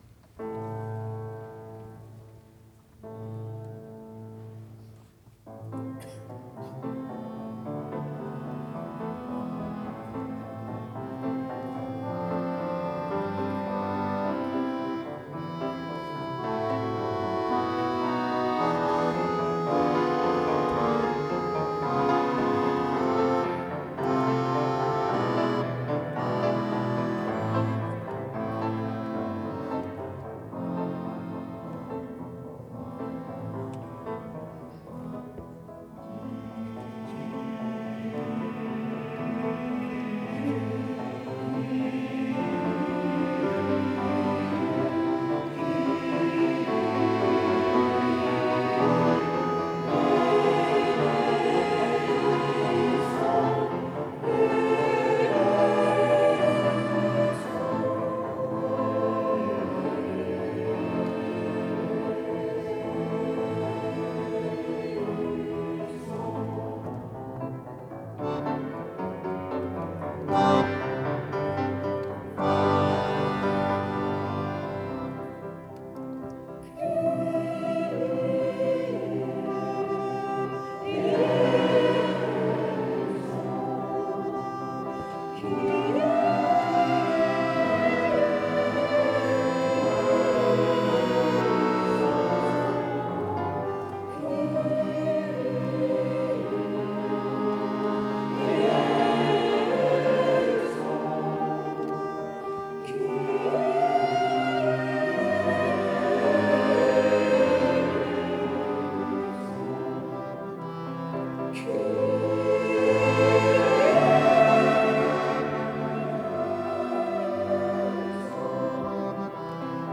met Groot Maqam Koor | 22 juni 2024
Op 22 juni 2024 bracht MAQAM in een stampvolle Antonius van Paduakerk in Nijmegen de Petite Messe Solennelle van Rossini onder de titel Un Cri du Coeur.
Niet met orkest, maar met harmonium en één piano.
Ook Maqam doet daaraan mee: een beknopte begeleiding, met een wat groter koor.
piano
harmonium
sopraan
alt
tenor